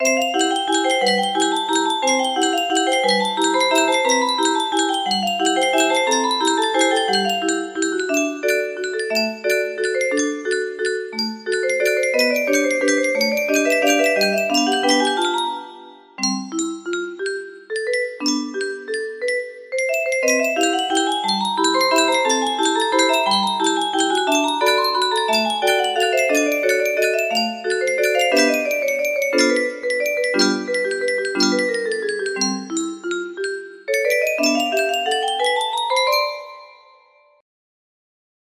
I think this is super cute!